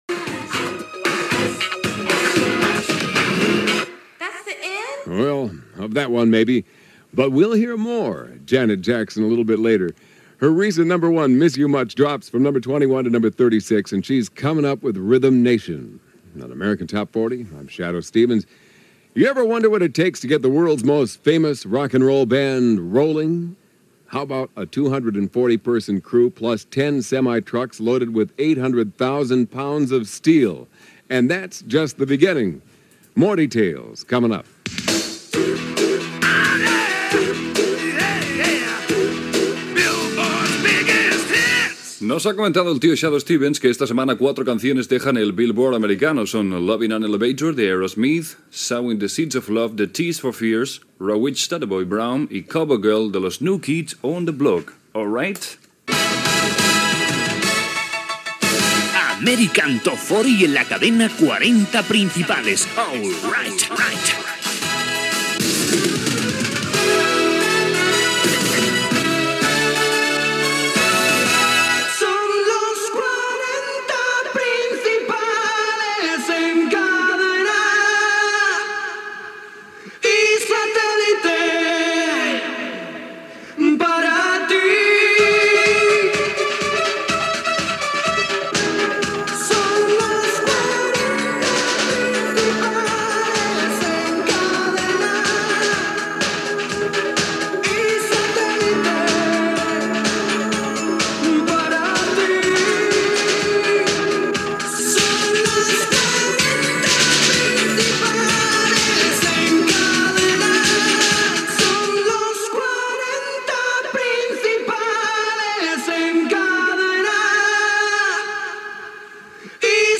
Cançons que deixen el llistat de Bill Board als EE.UU., indicatiu del programa, indicatiu de la cadena, indicatiu del programa i tema que ocupa la desena posició de la llista
Musical